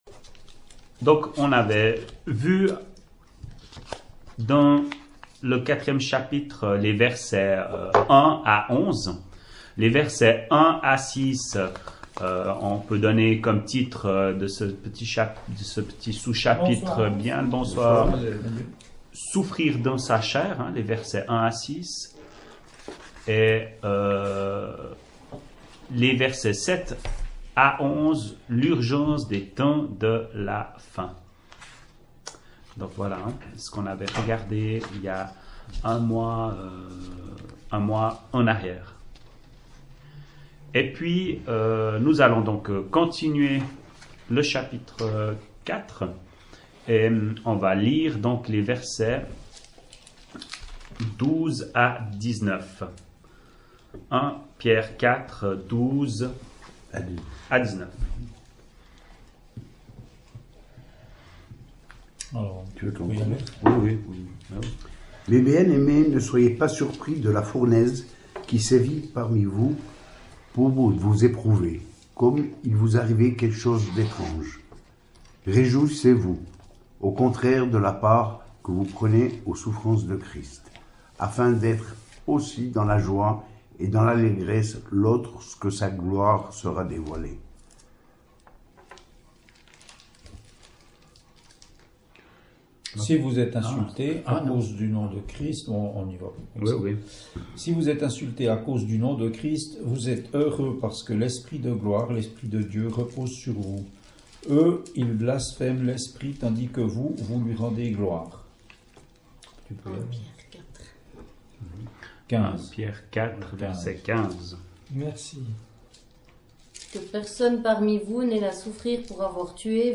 [Chapelle de l’Espoir] - Étude biblique : Première Épître de Pierre, 10ème partie
ÉTUDE BIBLIQUE : Evole, le 28.03.2018